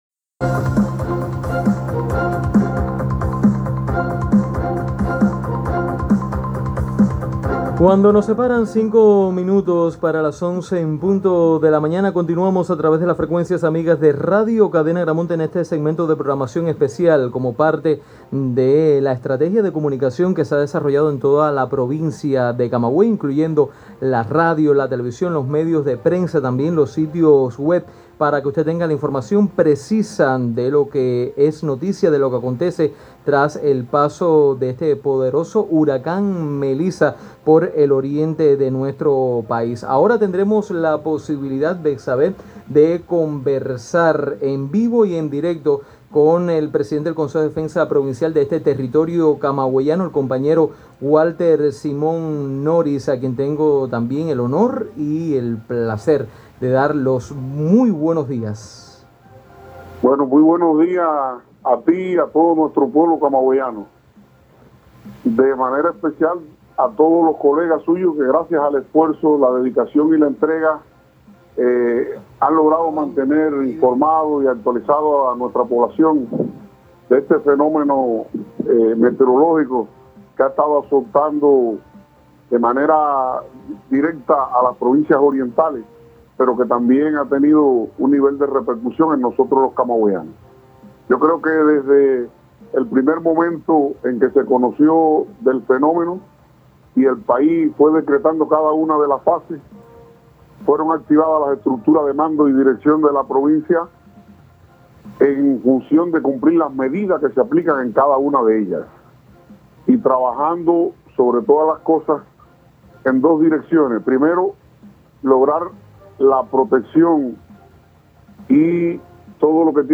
Walter Simón Noris, presidente del Consejo de Defensa Provincial (CDP), compareció vía telefónica en la programación especial de Radio Cadena Agramonte donde informó que el territorio tuvo afectaciones mínimas por el paso del huracán Melissa, aunque se mantiene la posibilidad de inundaciones costeras en la zona norte de la región.